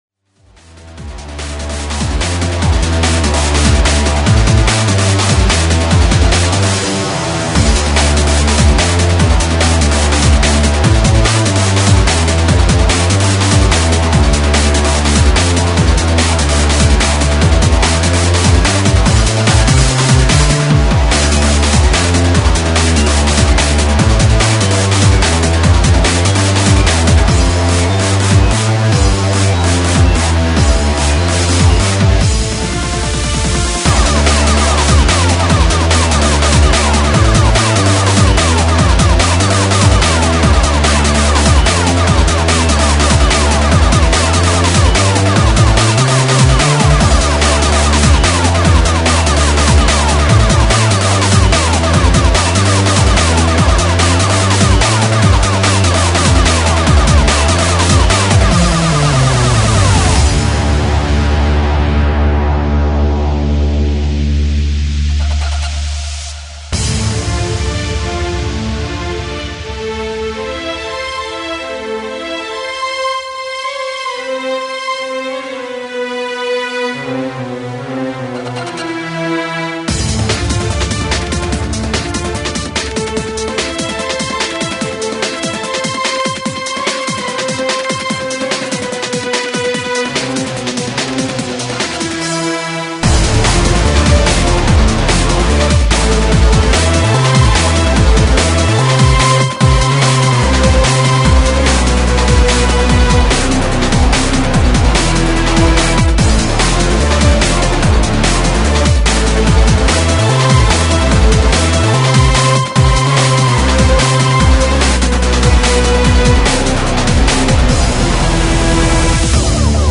Breaks